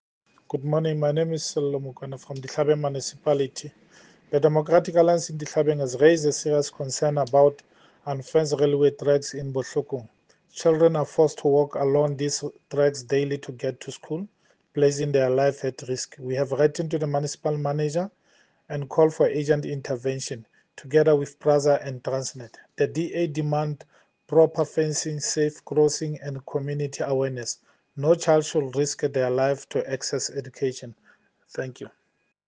Sesotho soundbites by Cllr Sello Mokoena and